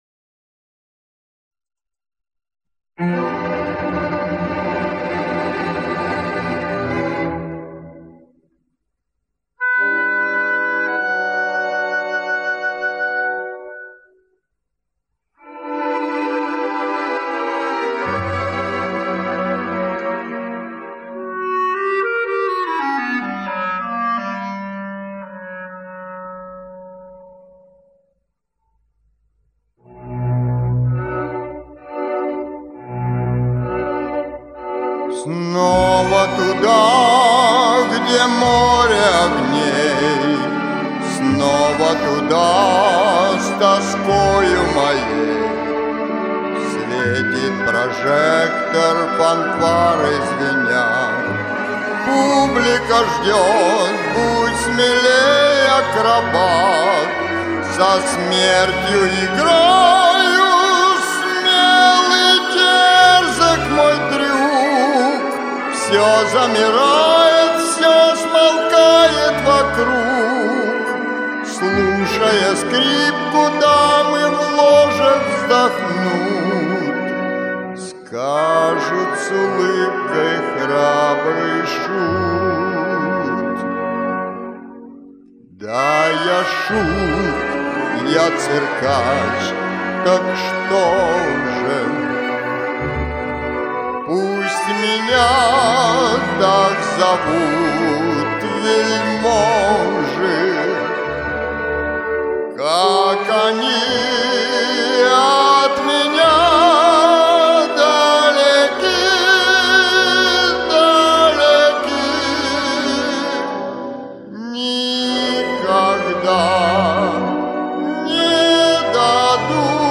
У соперников разные диапазоны и тембры голосов.